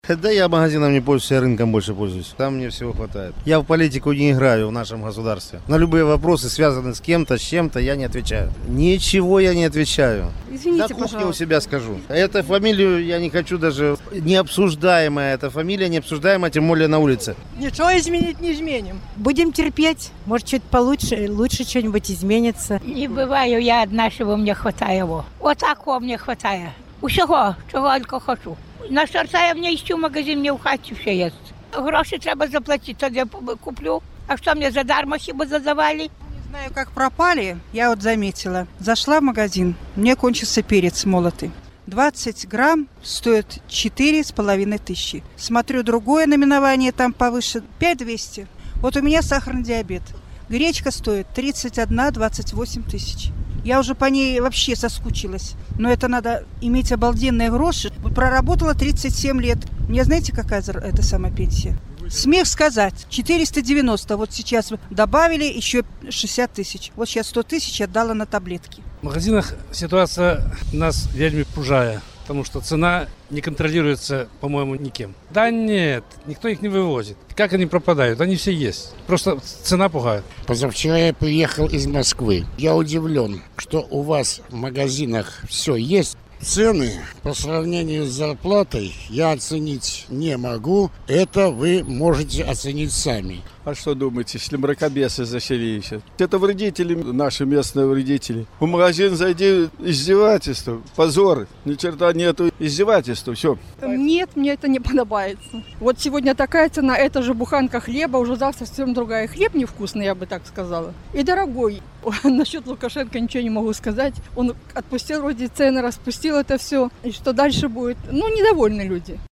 Апытаньне ў Горадні: Як зьмяніўся асартымэнт харчовых крамаў?